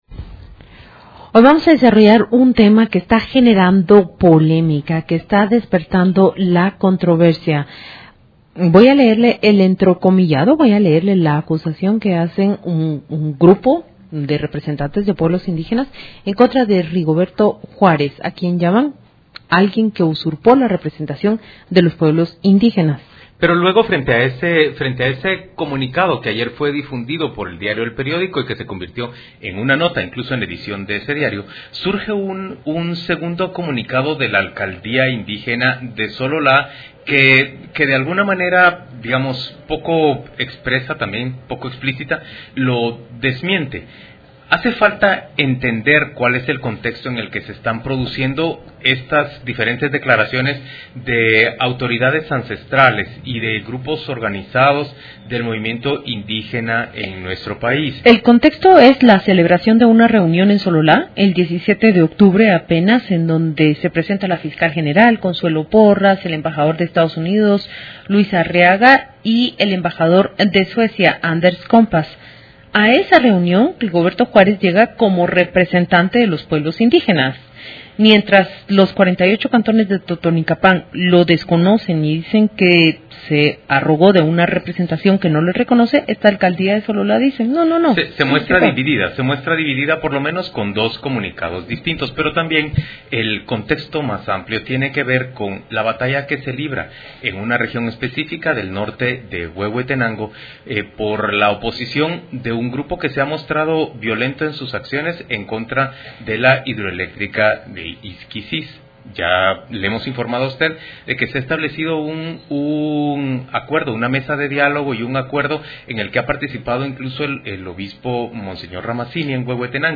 CON CRITERIO/RADIO INFINITA: Entrevista